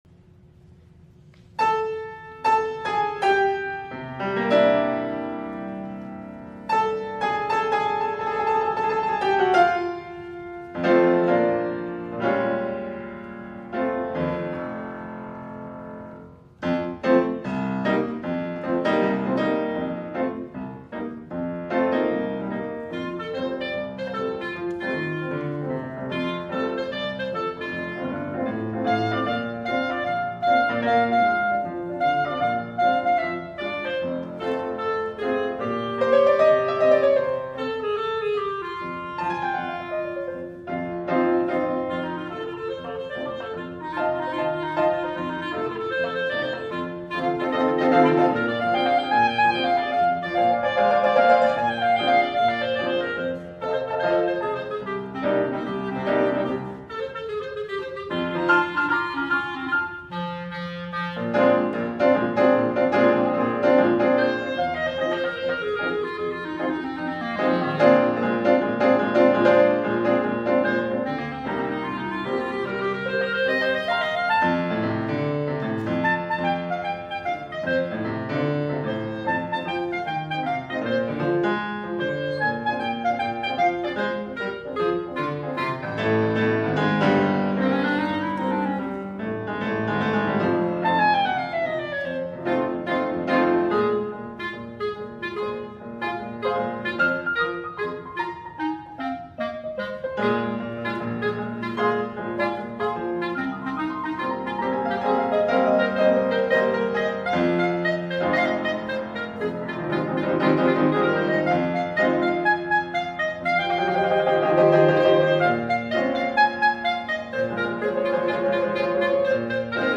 for Clarinet and Piano (2005)